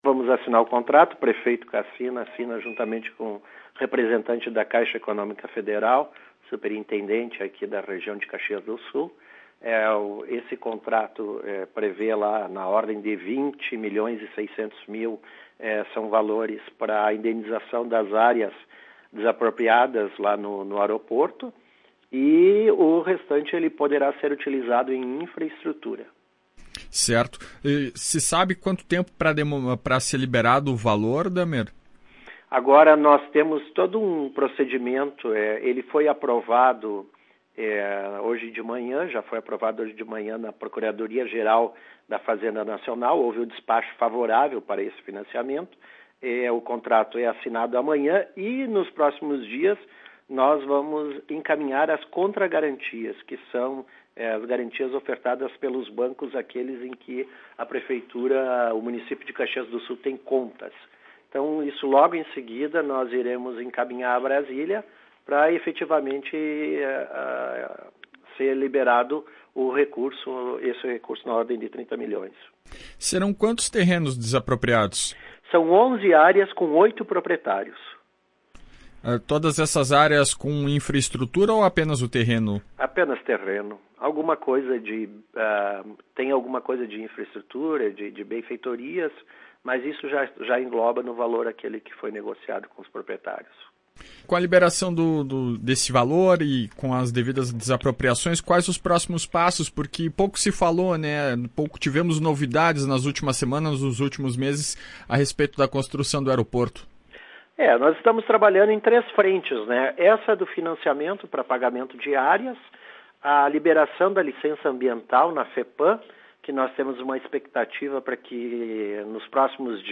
O secretário de Gestão e Finanças e Planejamento do município, Paulo Dahmer, explica que parte deste valor será utilizado para a infraestrutura da obra.